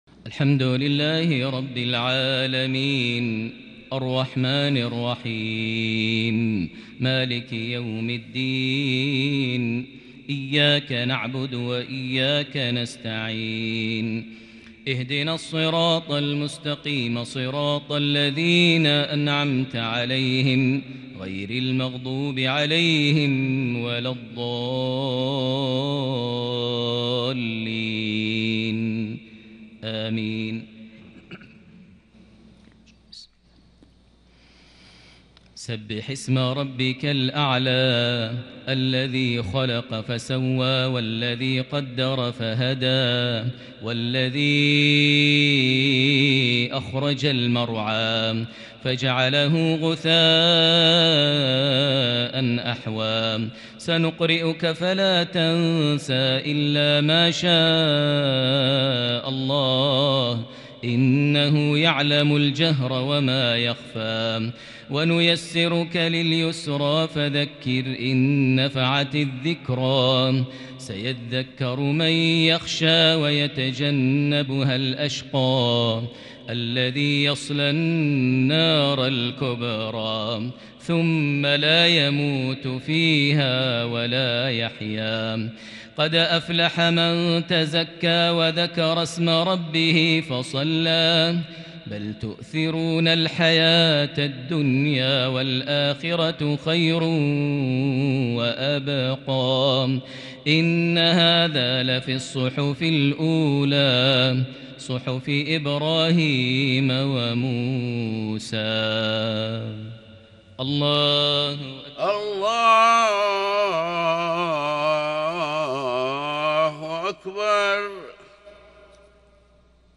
تلاوة كرديه لسورتي الأعلى - الغاشية لصلاة الجمعة ٩ محرم ١٤٤٢هـ > 1442 هـ > الفروض - تلاوات ماهر المعيقلي